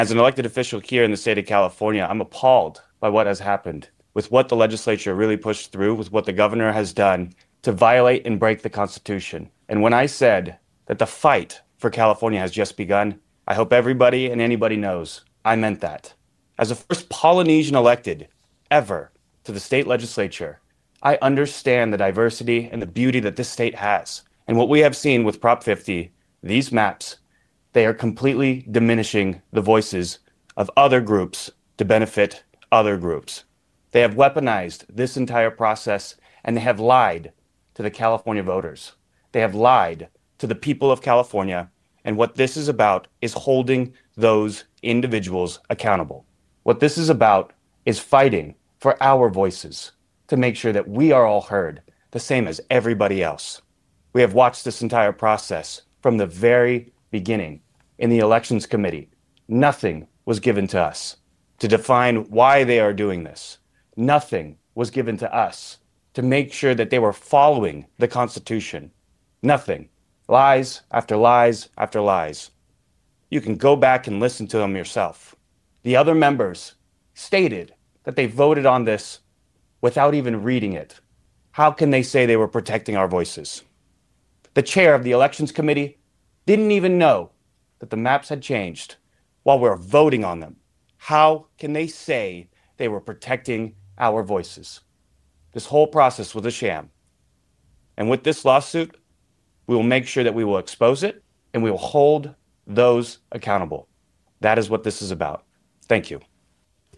The Clovis republican announced the federal lawsuit Wednesday morning at a press conference alleging the redistricting plan is unconstitutional and violates the 14th and 15th amendments.
tangipa-presser.mp3